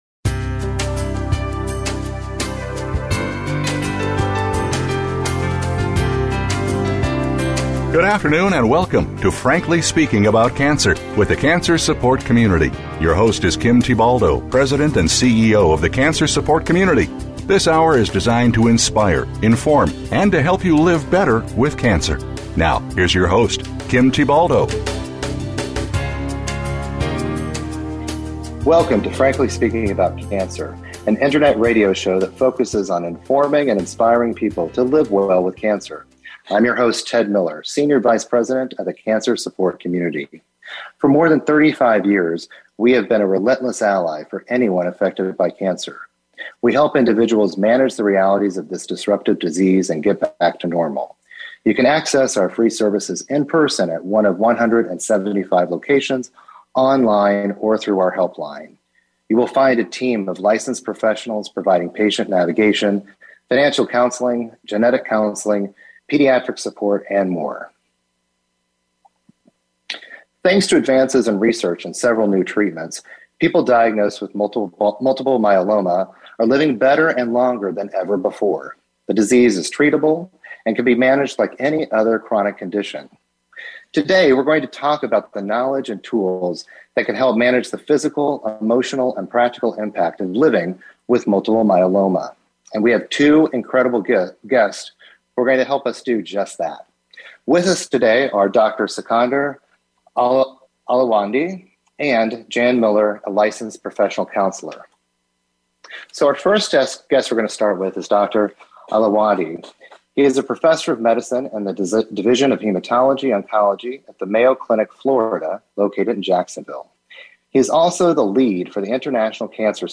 a licensed professional counselor.